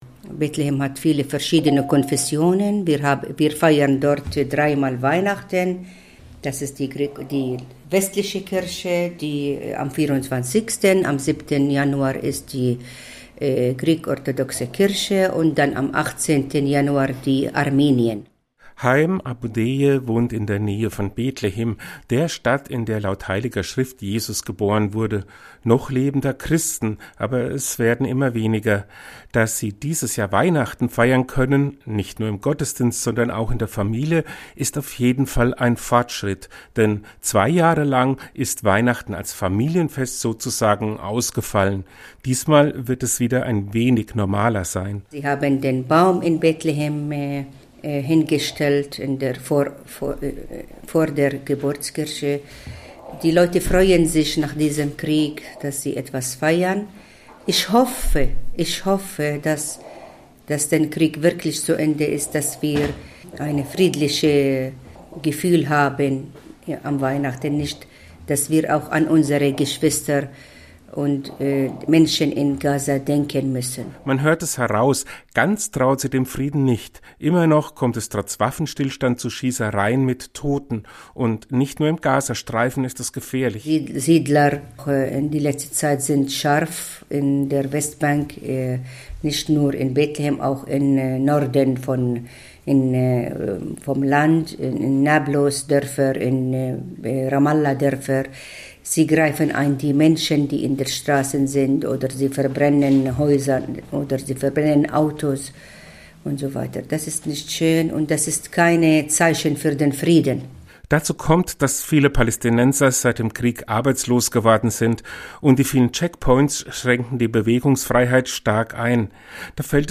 Geschichten und Berichte aus dem Bistum Würzburg